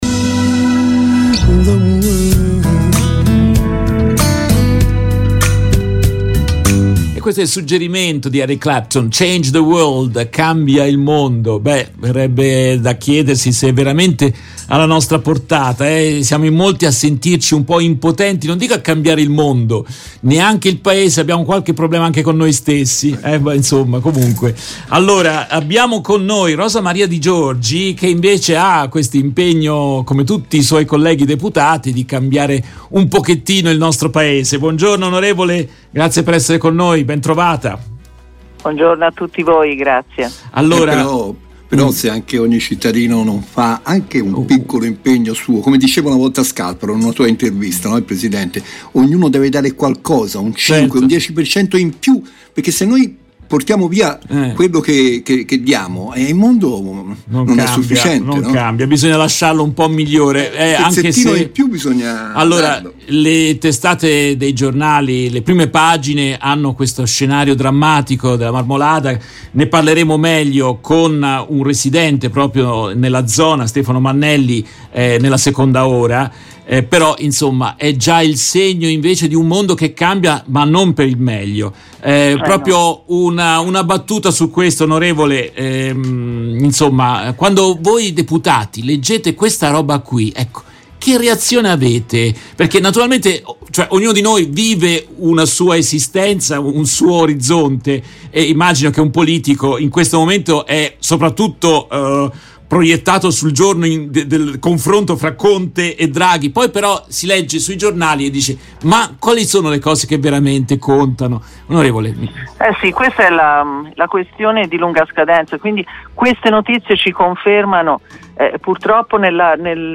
In questa intervista tratta dalla diretta RVS del 04 luglio 2022